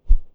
Close Combat Swing Sound 84.wav